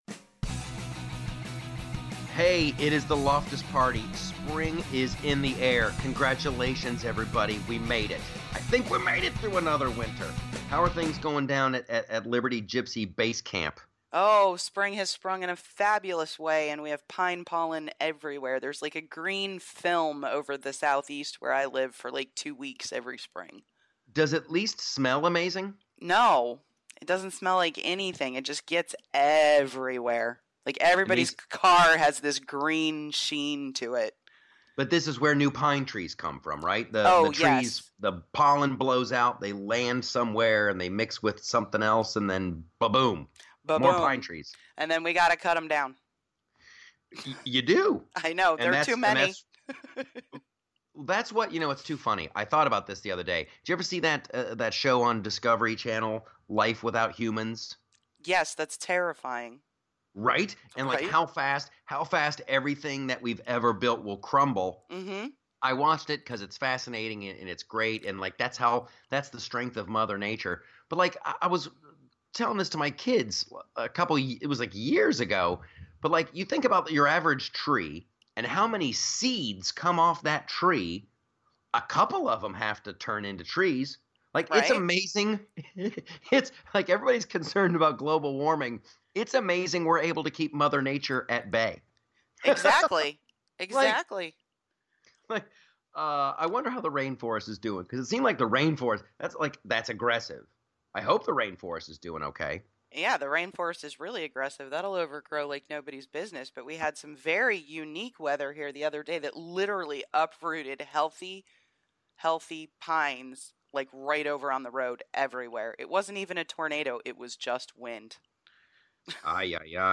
wacky Lawrence O'Donnell impression